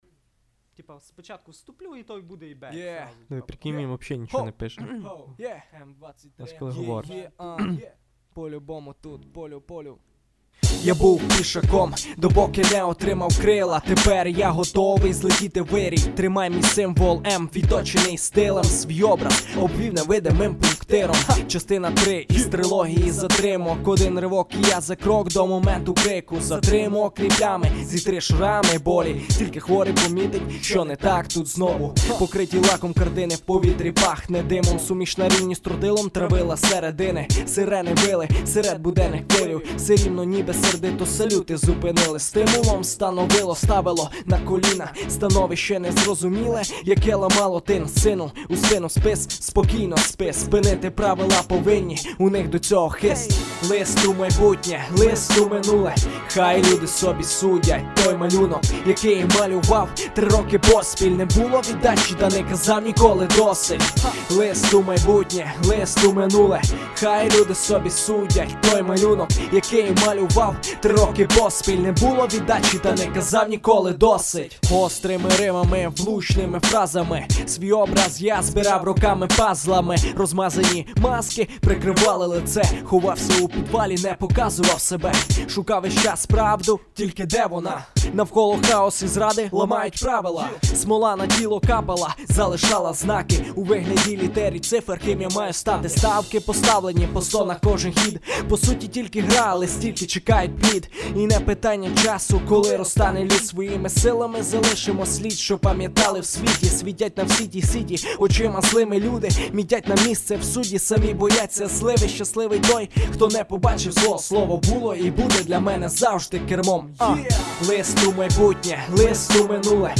украинский рэп
ха smile пасіб :)) шо цікаво, це тільки демо-варіант, майже не зведений...і багато, хто каже, шо на ріпіті слухають :))
та підходити - підходить, тіки пустий...треба доробити все